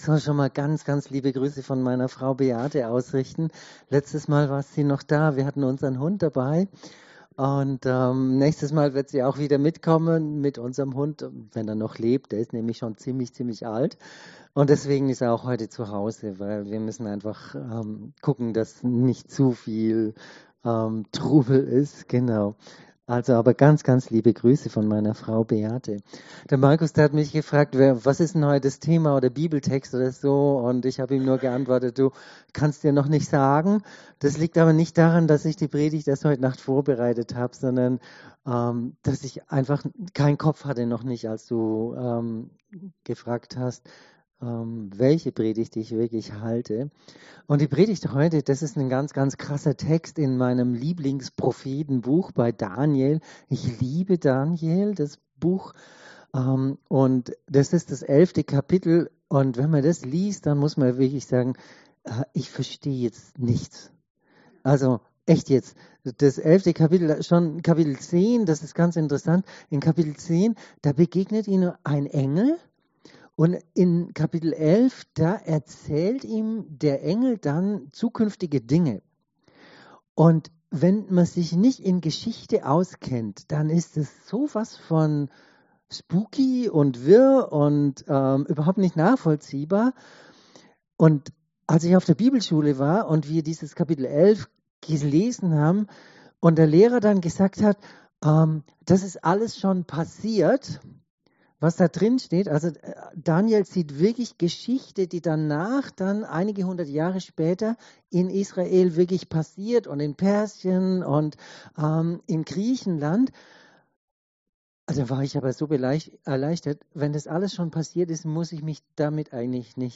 23.11.2025 Daniel 11,32 MP3 Audio herunterladen Zur Übersicht aller Predigten Beitragsnavigation ← Alle guten Gaben